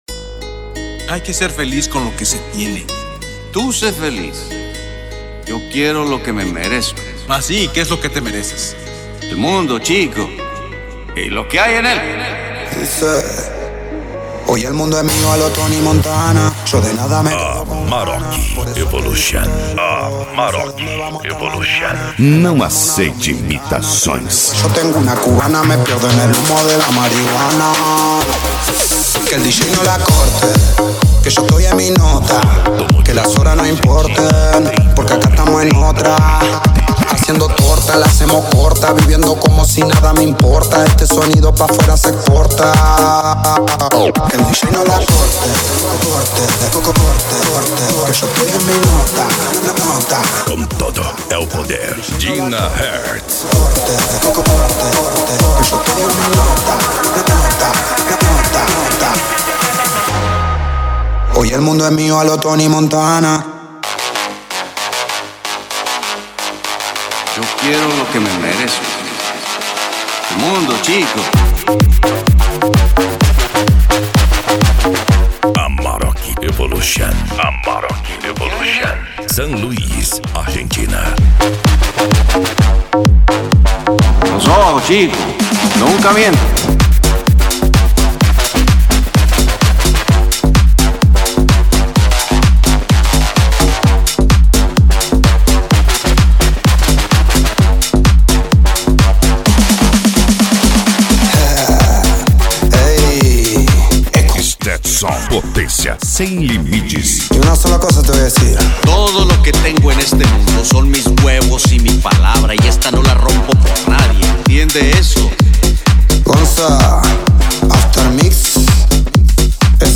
Funk
Remix